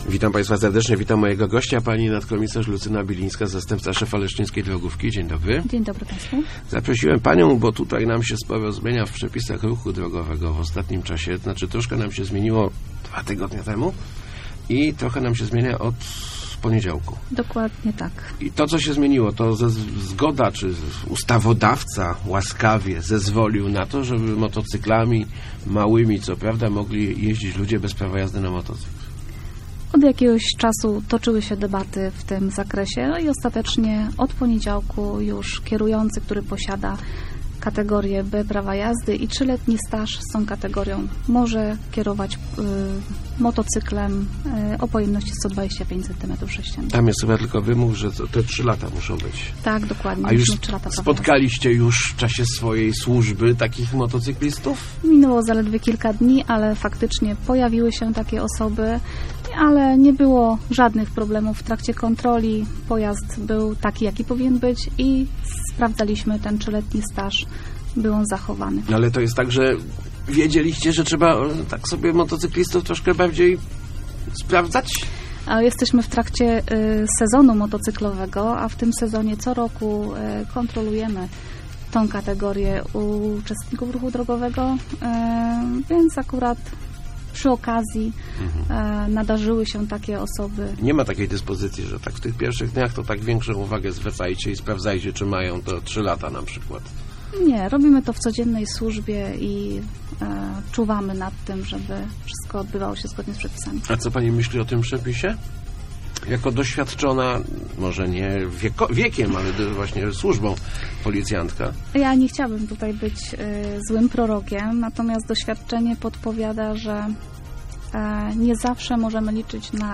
Start arrow Rozmowy Elki arrow Nowe przepisy dla motocyklistów i pieszych